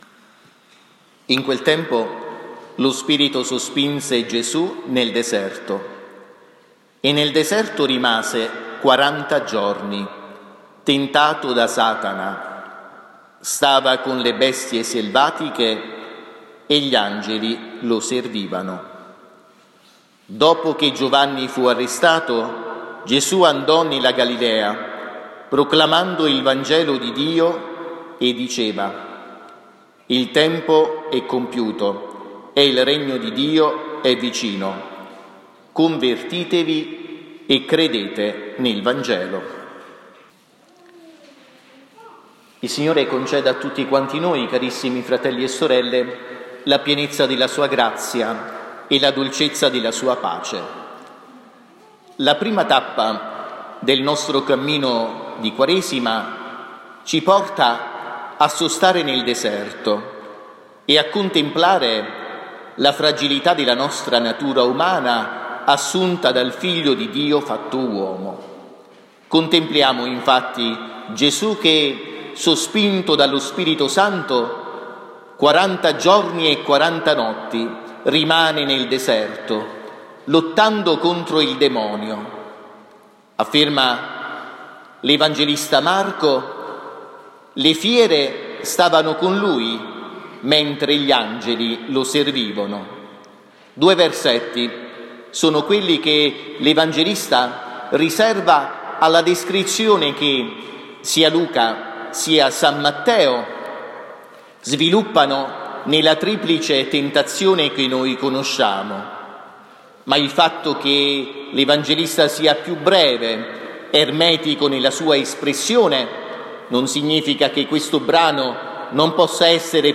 Omelia audio
I DOMENICA DI QUARESIMA (ANNO B) – 18 febbraio 2024